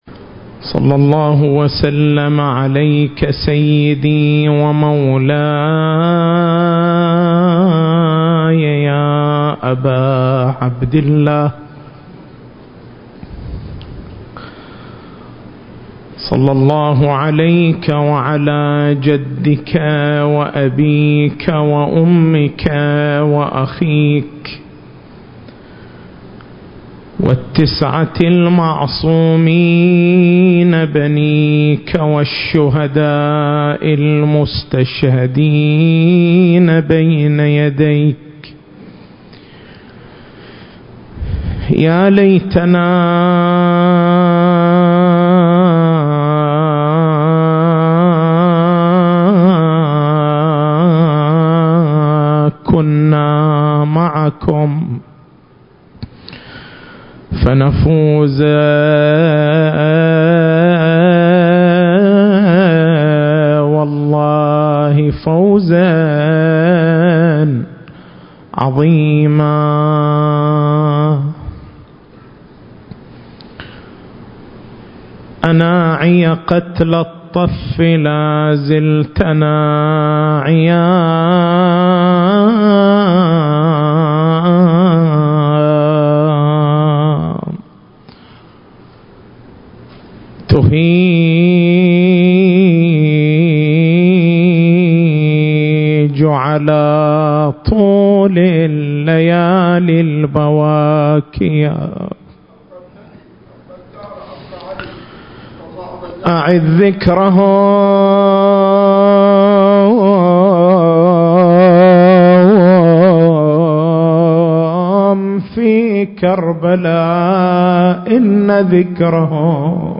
المكان: حسينية الحاج حبيب العمران التاريخ: 1440 للهجرة